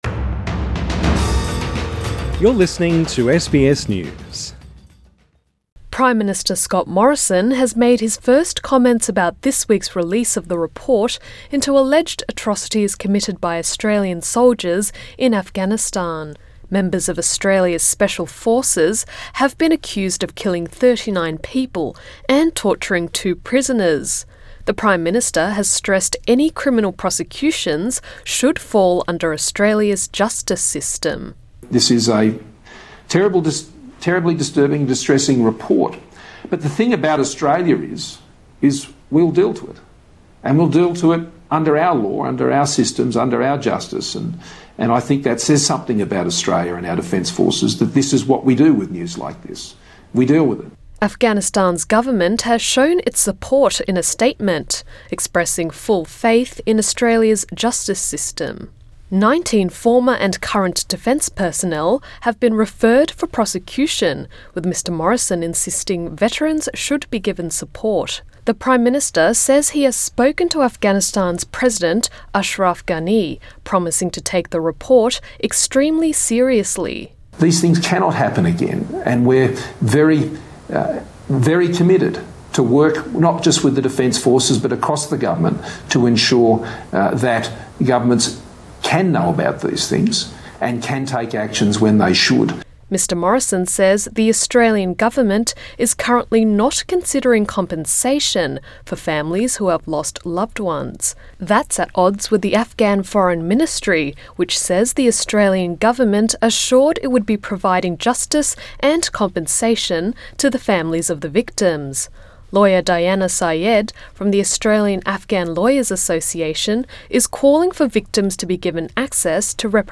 Australia’s Prime Minister at a virtual press conference Source: AAP